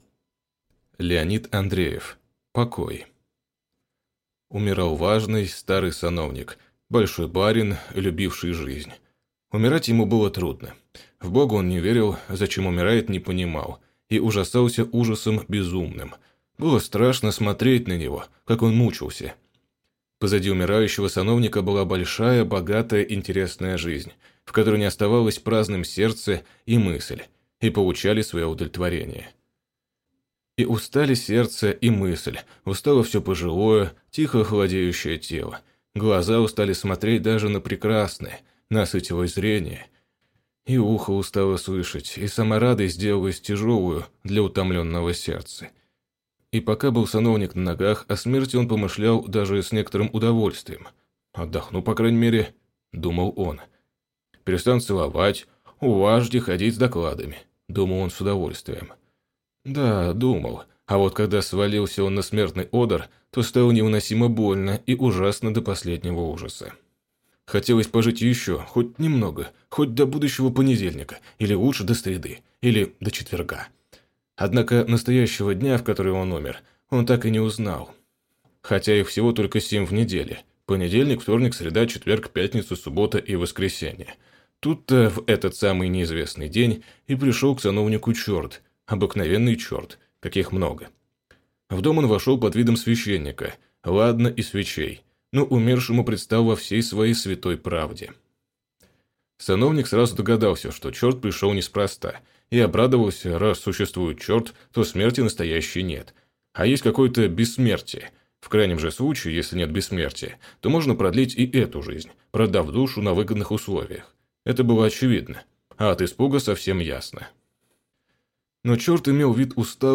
Аудиокнига Покой | Библиотека аудиокниг